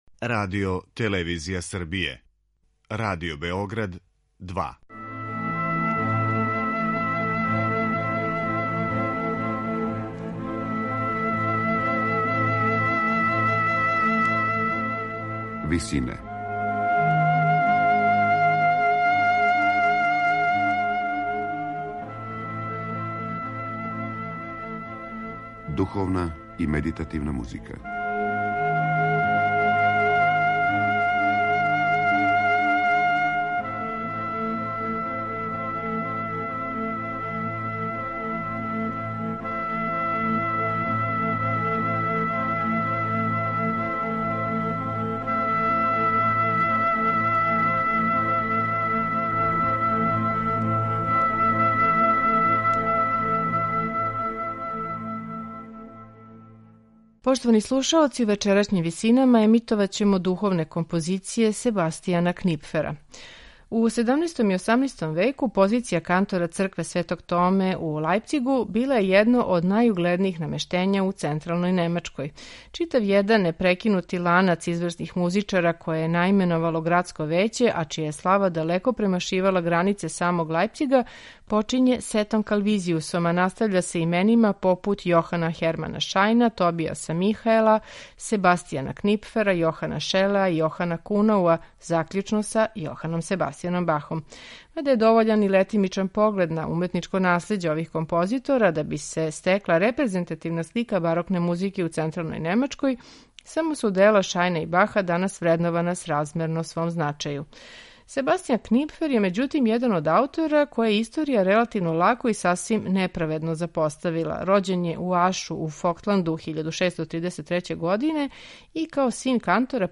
Себастијан Книпфер: Духовна музика
две коралне кантате